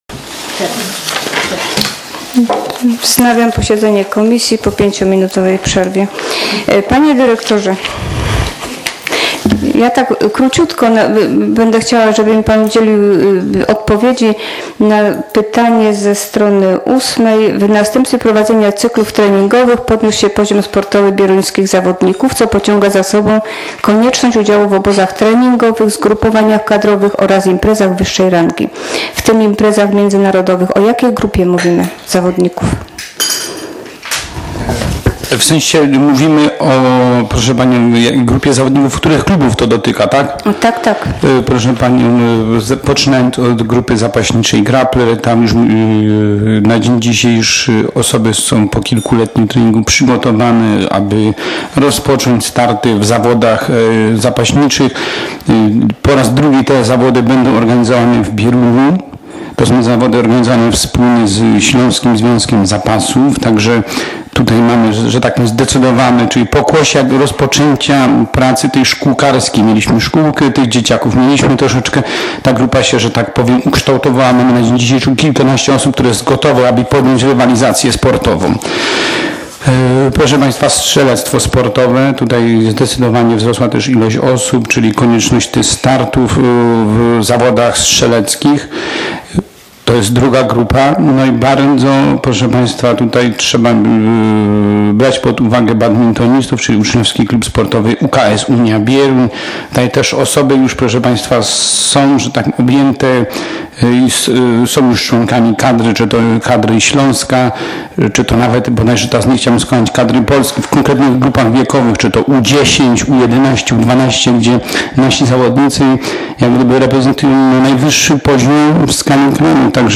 z posiedzenia Komisji Oświaty, Kultury, Sportu, Zdrowia i Opieki Społecznej w dniu 10.09.2015 r.